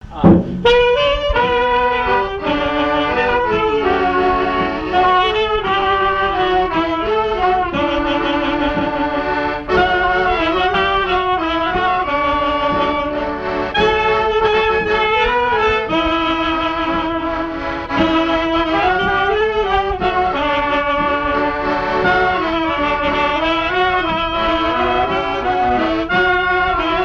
valse
danse : valse
Vie de l'orchestre et son répertoire, danses des années 1950
Pièce musicale inédite